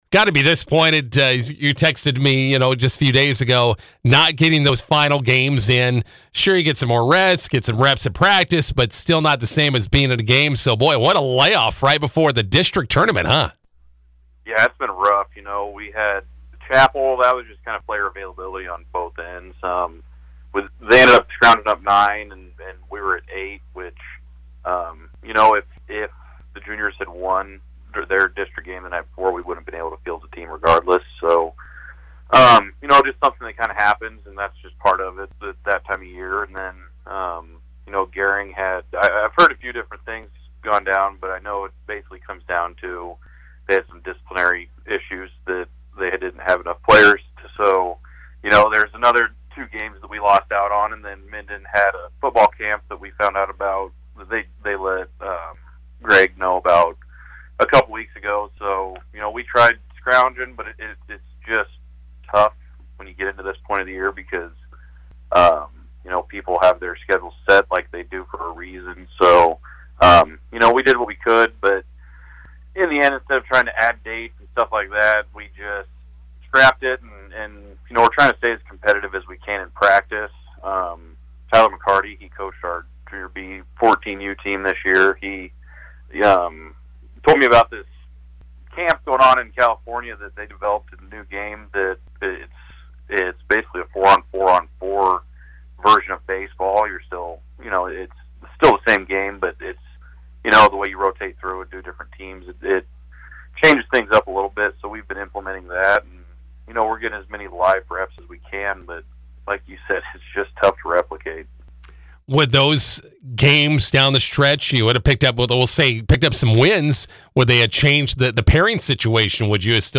INTERVIEW: MNB Bank Seniors hosting B6 District Tournament, face Gothenburg tonight.